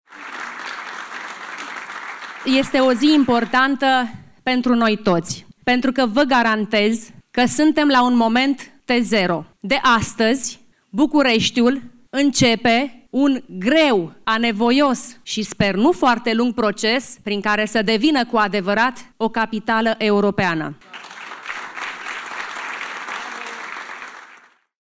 Au existat însă și vremuri în care doamna Firea era aplaudată la scenă deschisă. Acum exact doi ani, la jurământul de primar.